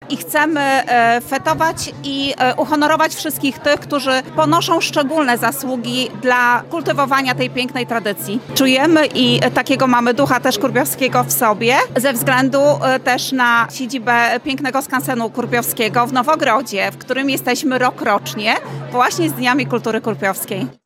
Wicestarosta łomżyński, Anna Gawrych podkreślała jak ważne jest pielęgnowanie tradycji kurpiowskiej i przekazywanie jej młodemu pokoleniu: